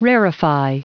Prononciation du mot rarefy en anglais (fichier audio)
Prononciation du mot : rarefy